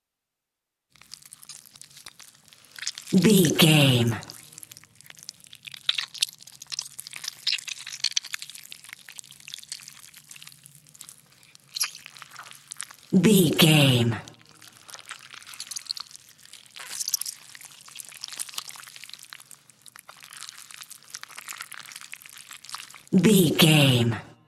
Creature eating flesh juicy
Sound Effects
Atonal
scary
ominous
eerie
horror